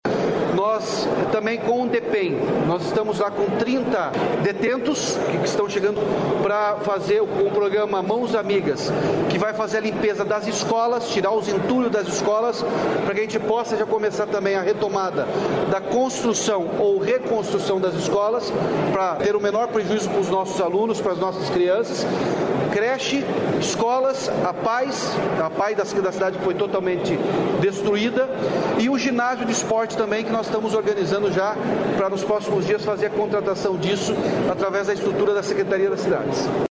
Sonora do governador Ratinho Junior sobre o envio de presos para reconstruir escolas e Apae de Rio Bonito do Iguaçu